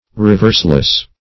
Reverseless \Re*verse"less\